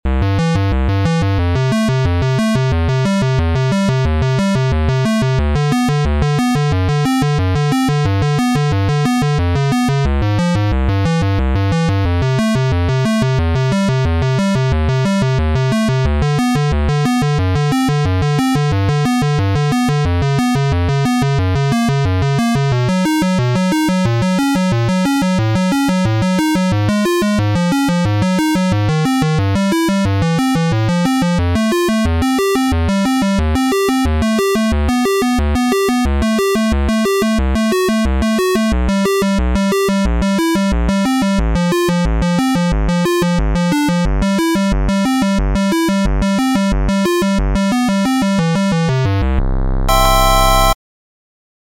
Op. 4/1 Etude for Piano Two Hands